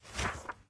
Minecraft Version Minecraft Version snapshot Latest Release | Latest Snapshot snapshot / assets / minecraft / sounds / item / book / open_flip2.ogg Compare With Compare With Latest Release | Latest Snapshot
open_flip2.ogg